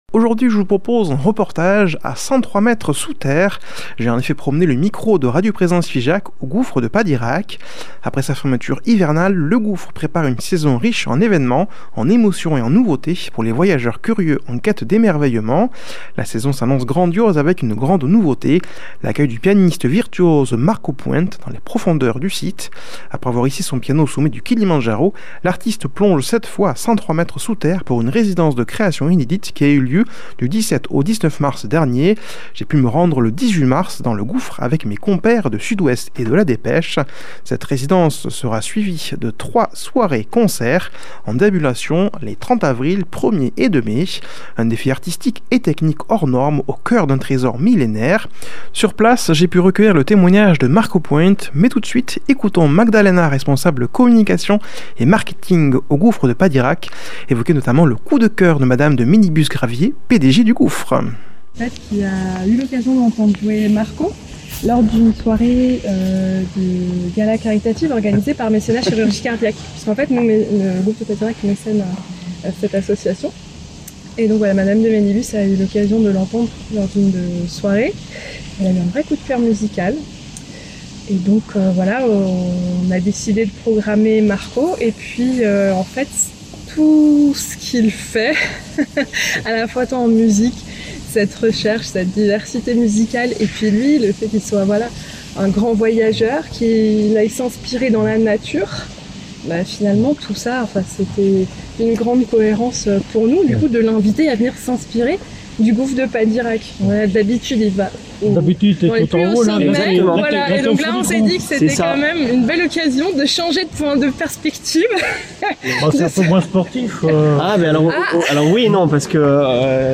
Il a promené le micro de Radio Présence Figeac au Gouffre de Padirac. Après sa fermeture hivernale, le Gouffre prépare une saison riche en événements, en émotions et en nouveautés pour les voyageurs curieux en quête d'émerveillement.